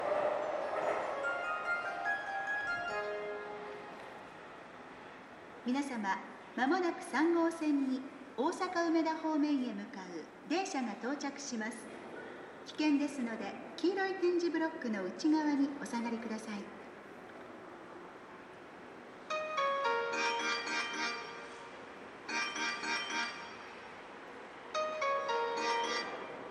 接近放送各駅停車　雲雀丘花屋敷行き接近放送です。